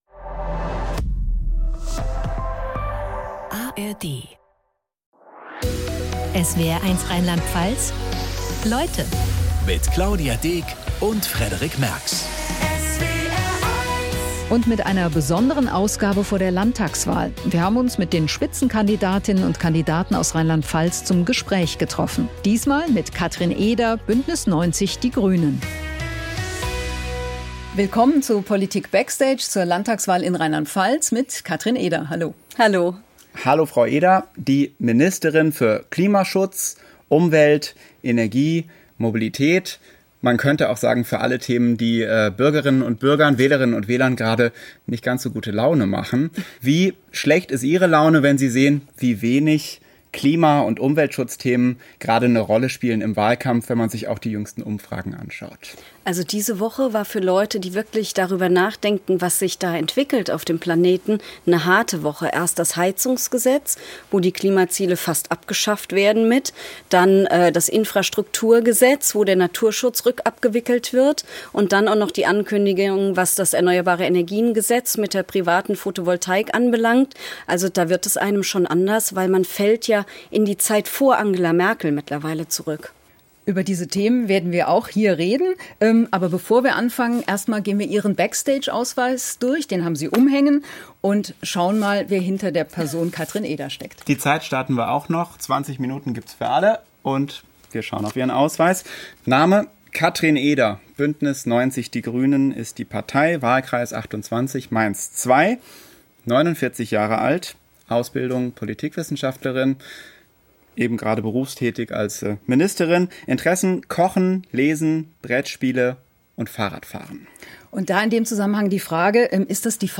Weitere Informationen zum Interview finden Sie hier: